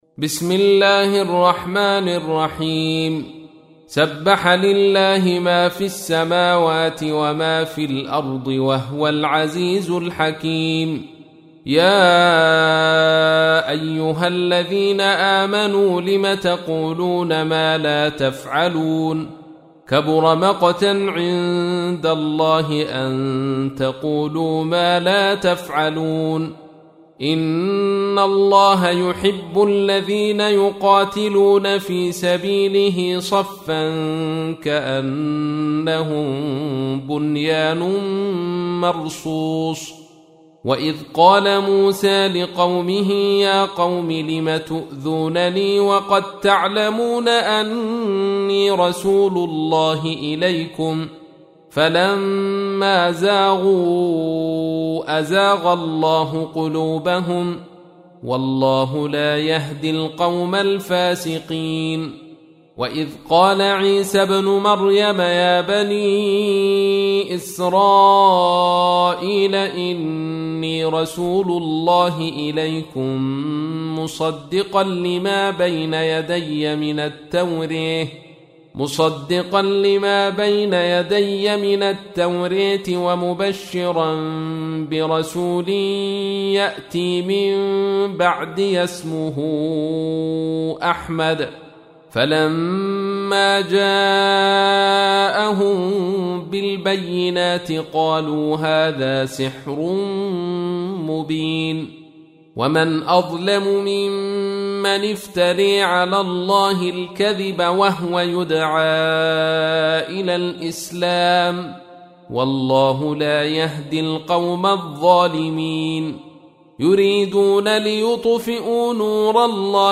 تحميل : 61. سورة الصف / القارئ عبد الرشيد صوفي / القرآن الكريم / موقع يا حسين